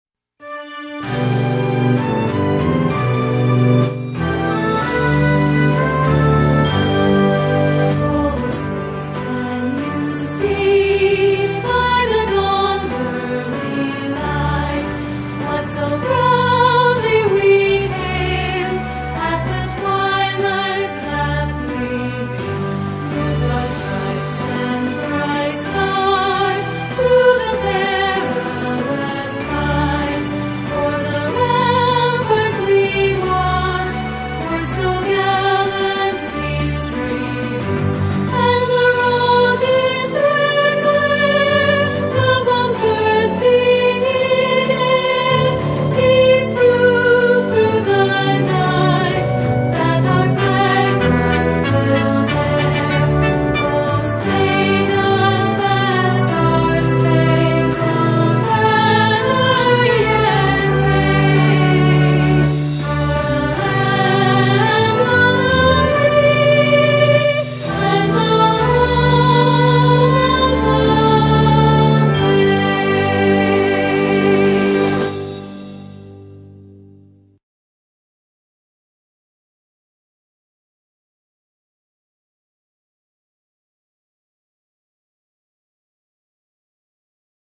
Star Spangled Banner with Lyrics, Vocals, and Beautiful Photos.mp3